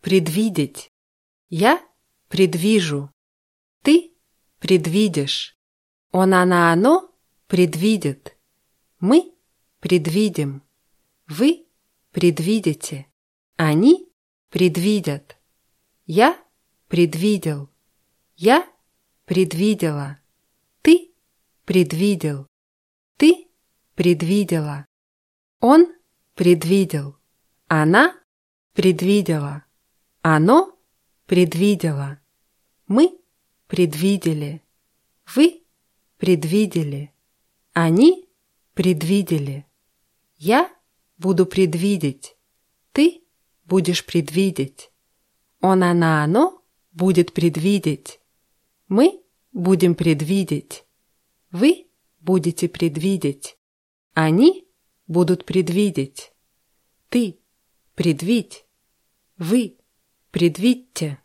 предвидеть [prʲidwʲídʲitʲ]